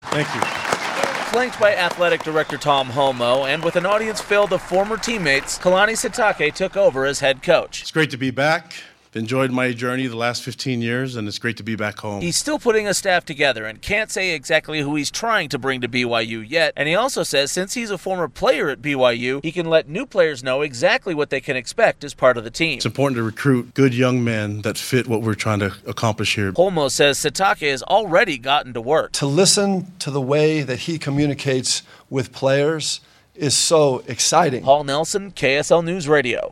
Kalani Sitake held his first press conference as the new head coach for BYU Football, and he says he can't wait to get to work.